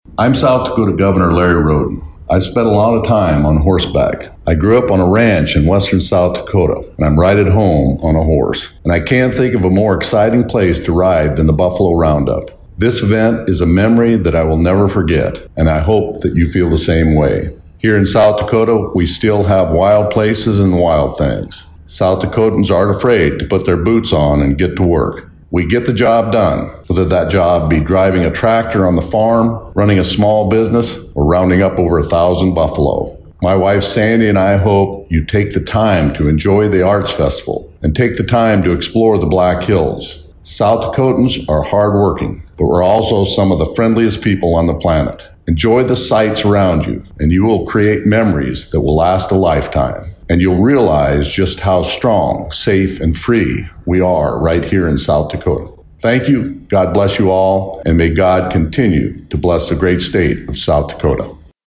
South Dakota Governor Larry Rhoden was among the participants this year, and even lent his voice to some of the broadcast messages.
Hear Governor Rhoden's roundup broadcast message.
Governor Rhoden Roundup Message.mp3